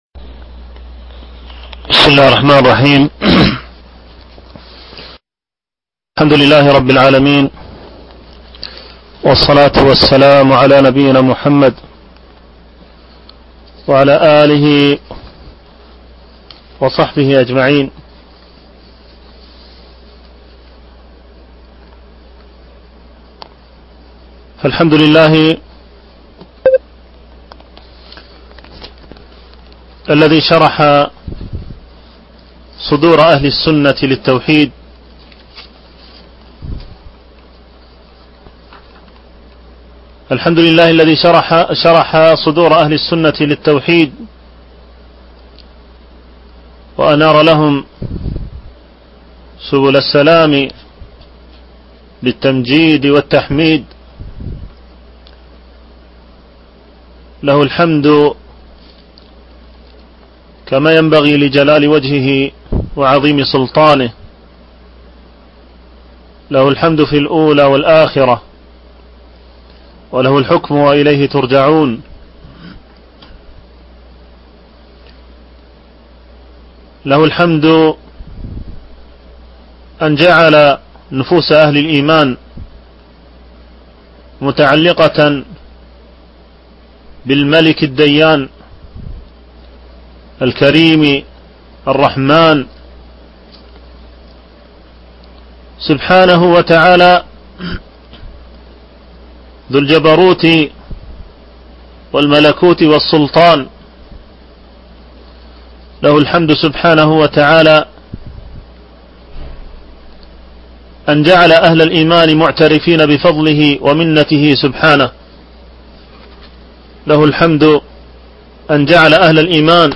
شرح كتاب التوحيد - الدرس الأول